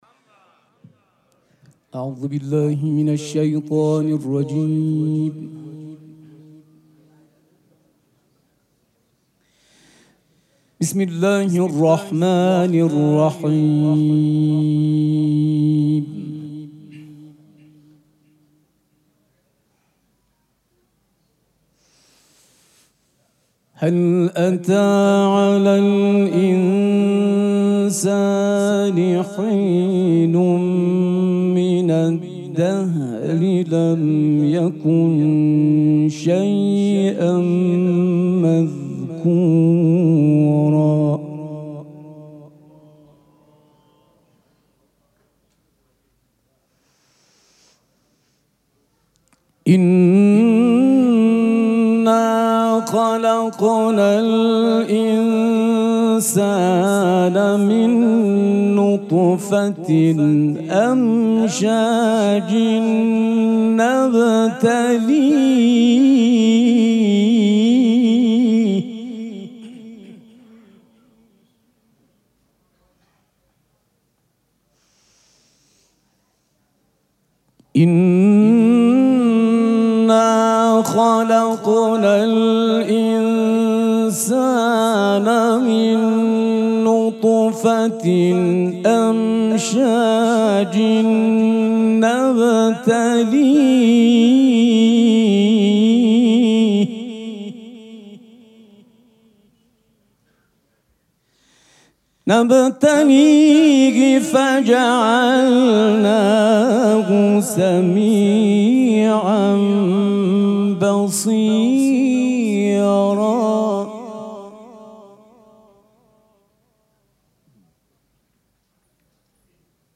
شب چهارم مراسم عزاداری دهه دوم فاطمیه ۱۴۴۶
سبک اثــر قرائت قرآن